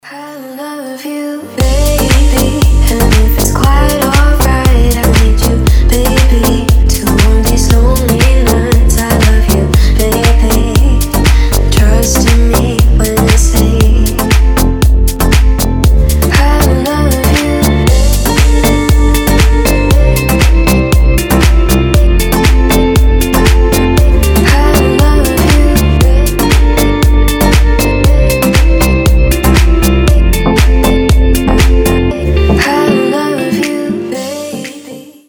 Рингтоны техно , Гитара
Deep house , Nu disco , Indie dance